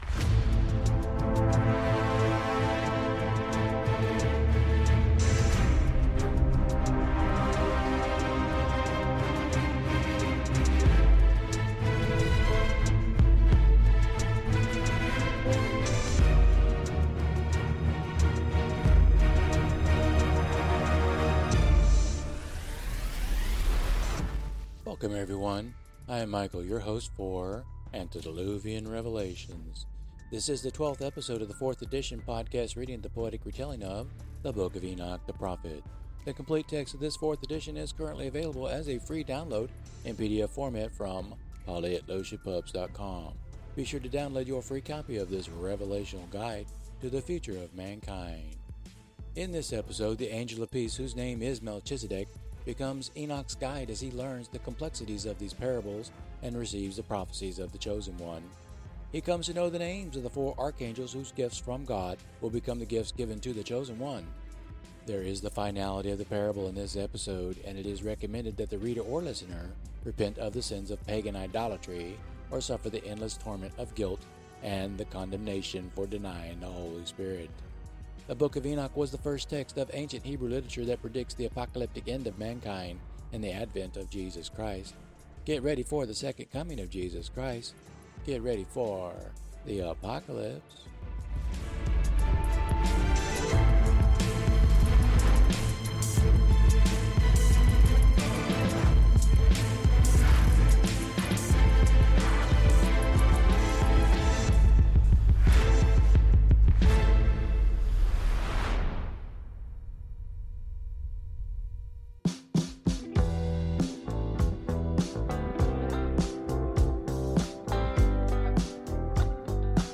This is the twelfth episode in this fourth edition of the podcast reading of the poetry within the book. These episodes will present Part Two of the epic poem, and the music selection will get a Jazz and Rock infusion.